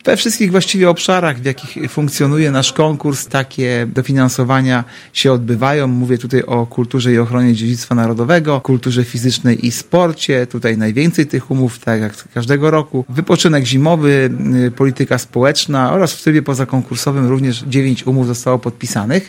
Cały czas trwa konkurs grantowy i cały czas przyjmowane są oferty organizacji na realizację konkretnych działań – mówi Artur Urbański – zastępca prezydenta Ełku.